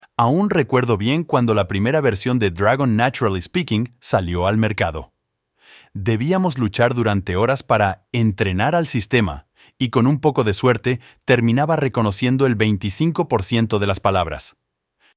Demo de ElevenLabs